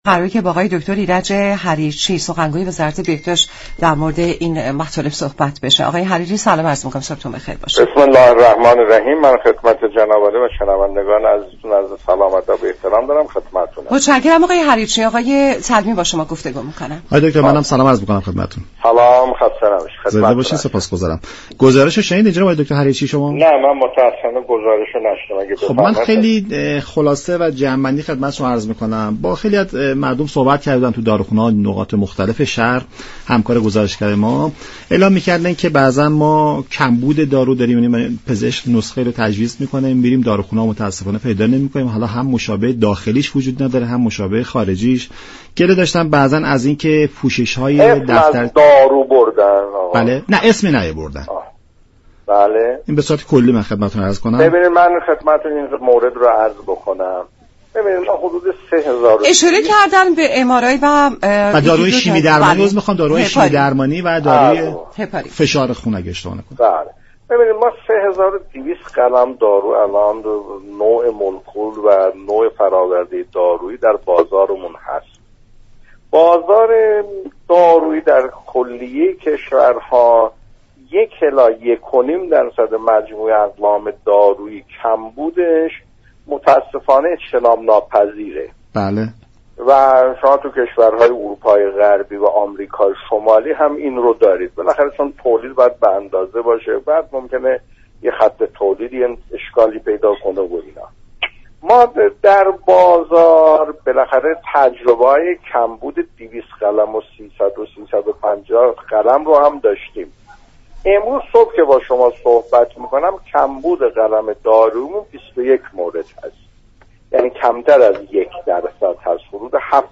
معاون وزارت بهداشت، درمان و آموزش پزشكی در گفت و گو با رادیو ایران گفت: به دلیل خصوصیات خط تولید تمام كشورها با معضل كمبود دارو مواجه هستند.